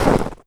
High Quality Footsteps
STEPS Snow, Run 06.wav